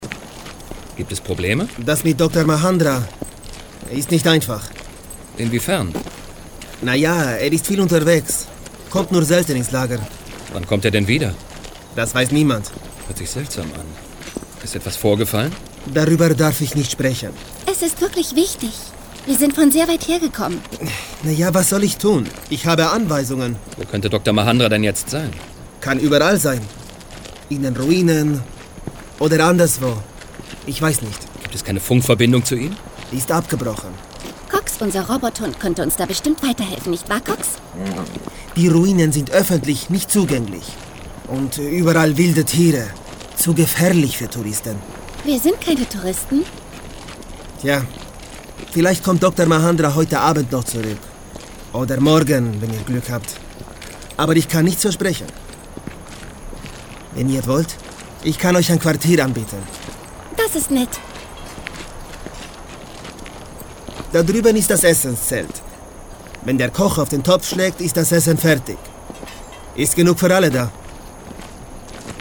Equipment: Soundblocker Sprecher - Box Mikro Neumann TLM 103 Channelstrip focusrite ISA 220 Wandler Mini - Me von Apogee m-box Muttersprache: deutsch und spanisch
Sprechprobe: Sonstiges (Muttersprache):
Spanischer Akzent.mp3